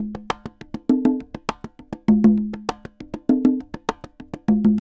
Congas_Salsa 100_5.wav